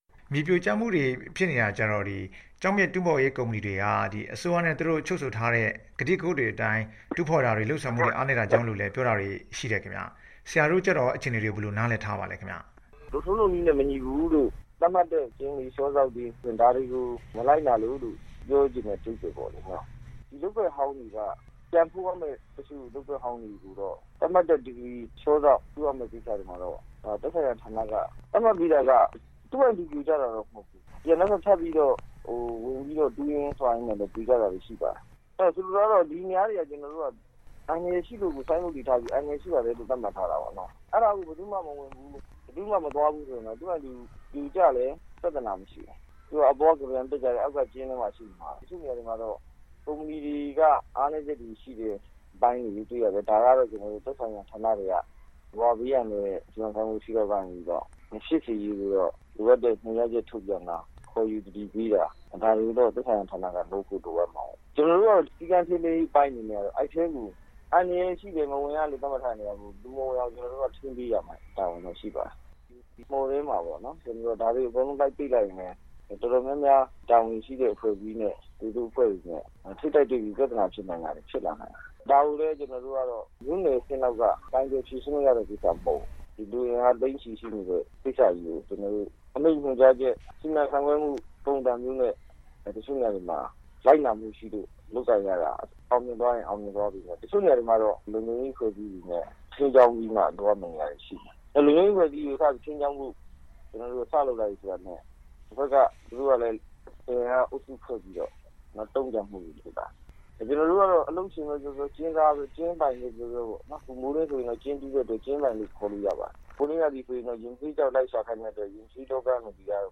ကချင်ပြည်နယ် ဖားကန့် ကျောက်စိမ်းတွင်းဒေသမှာ တောင်ကမ်းပါးယံနဲ့ စွန့်ပစ်မြေစာပုံ ပြိုကျမှုတွေ လျော့နည်းဖို့အတွက် ဒီဒေသမှာ လုပ်ကိုင်နေသူအားလုံးဟာ ရှောင်ကြဉ်ရမယ့် အချက်တွေ၊ လိုက်နာရမယ့် အချက်တွေကို အတိအကျ လုပ်ဆောင်ဖို့ လိုအပ်တယ်လို့ ဖားကန့်မြို့နယ်အုပ်ချုပ်ရေးမှူး ဦးကျော်စွာအောင် က ပြောပါတယ်။
ဆက်သွယ် မေးမြန်းထားပါတယ်။